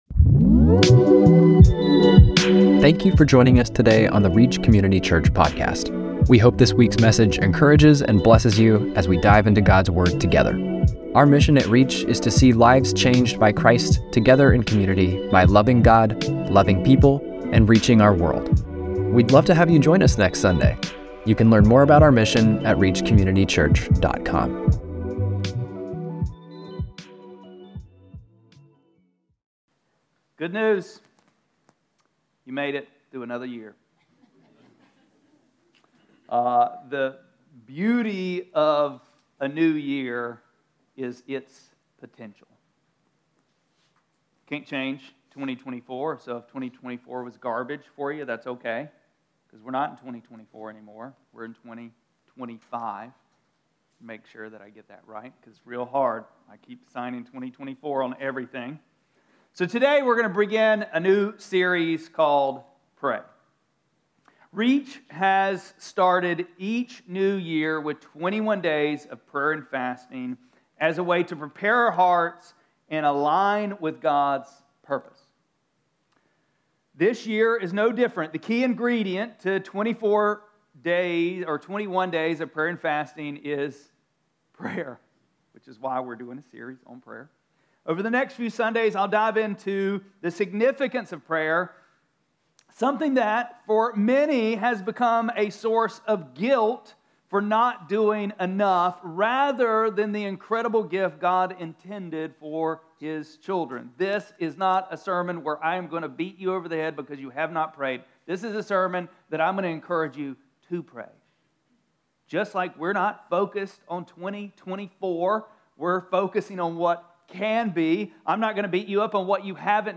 1-5-25-Sermon.mp3